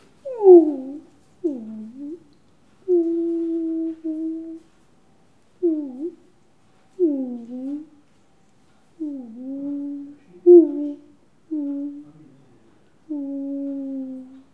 Les sons ont été découpés en morceaux exploitables. 2017-04-10 17:58:57 +02:00 2.4 MiB Raw History
bruit-animal_16.wav